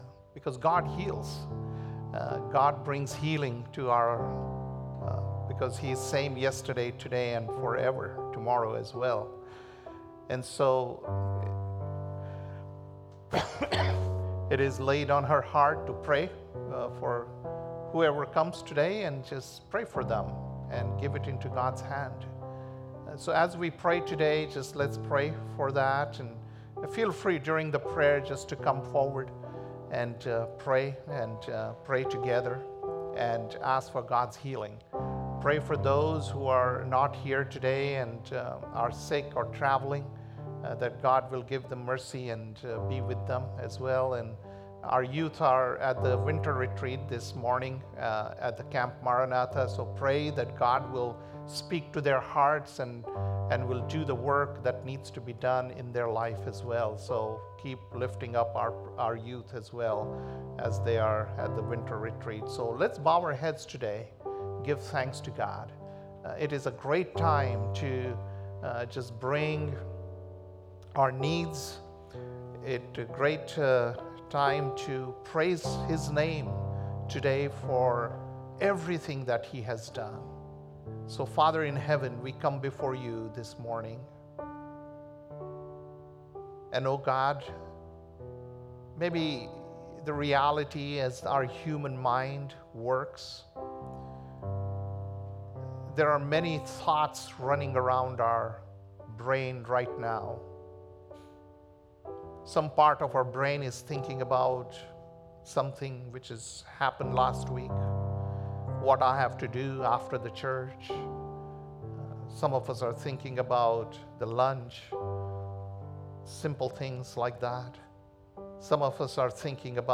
January 26th, 2025 - Sunday Service - Wasilla Lake Church